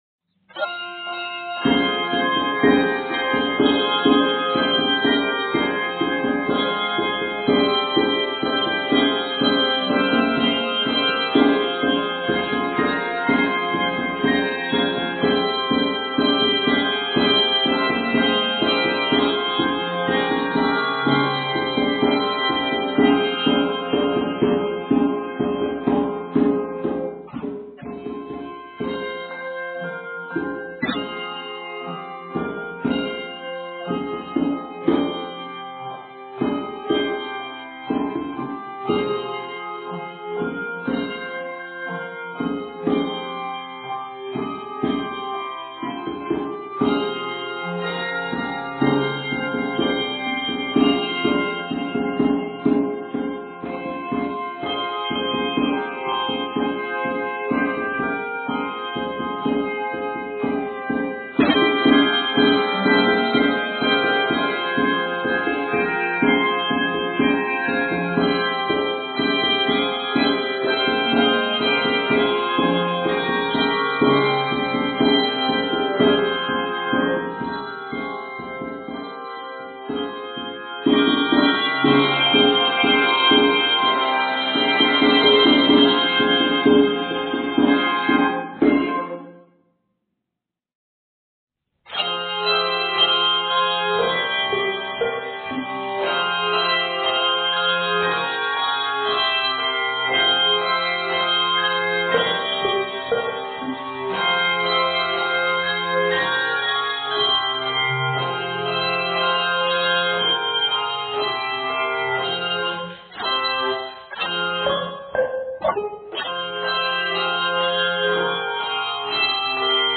hand drum or tambourine